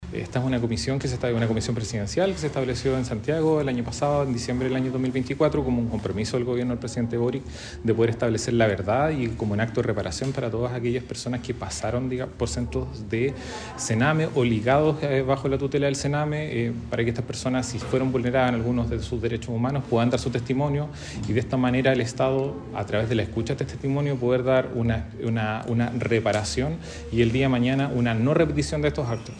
En esa línea, el seremi de Justicia, Carlos Uslar, señaló que con esta comisión “buscamos escuchar los testimonios de quienes fueron niños, niñas o adolescentes en centros del Sename y sufrieron vulneraciones a sus derechos”.